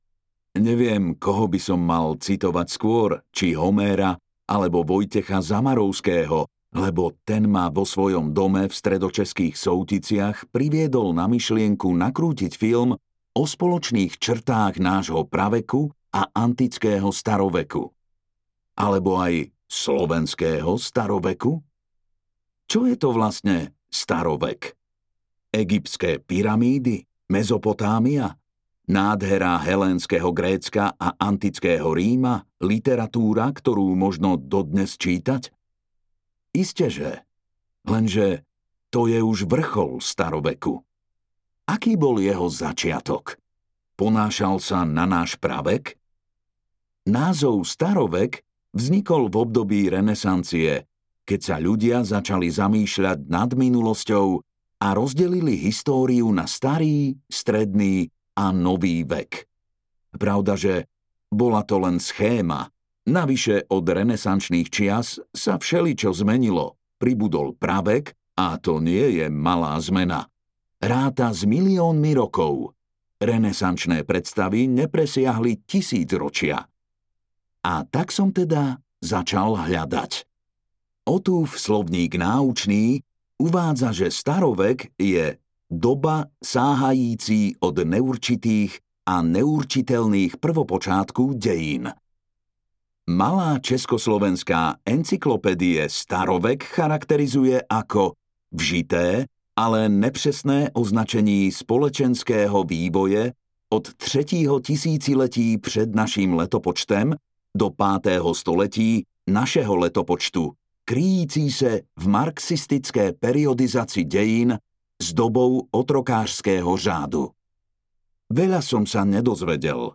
Stopy dávnej minulosti - Slovensko v staroveku audiokniha
Ukázka z knihy